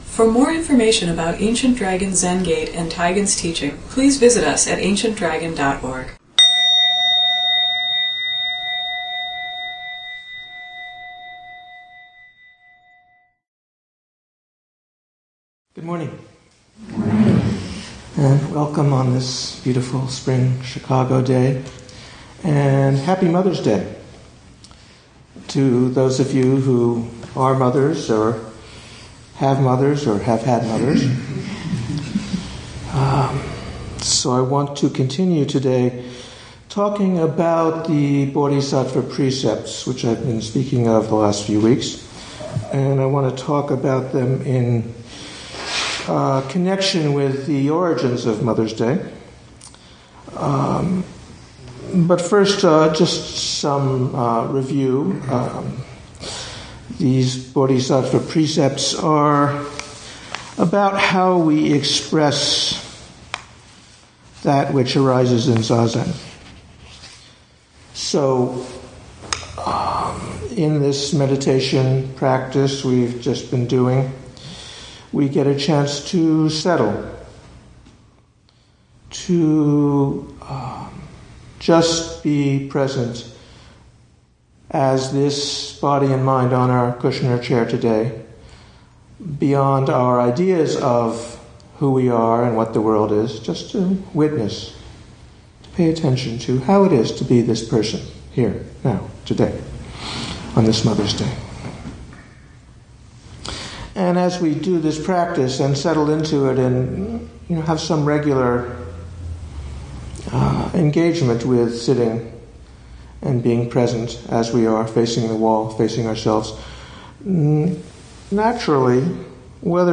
ADZG 108 ADZG Sunday Morning Dharma Talk